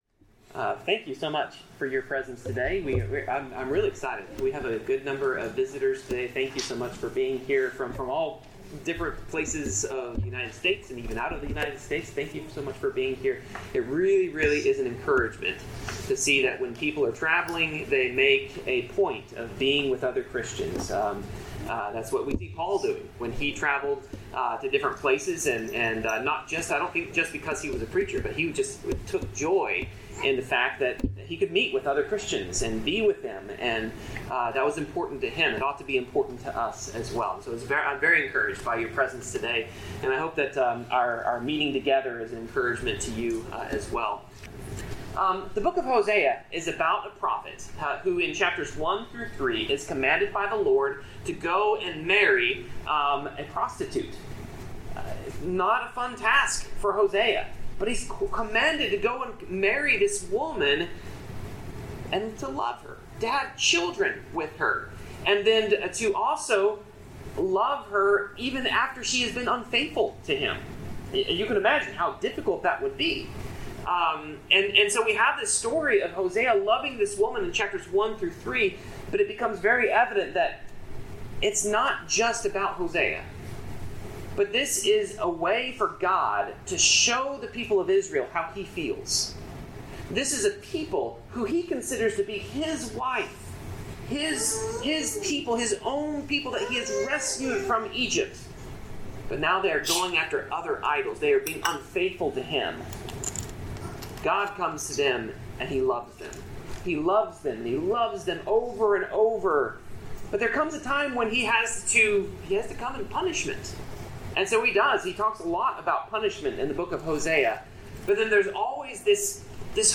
Passage: Hosea 12-14 Service Type: Sermon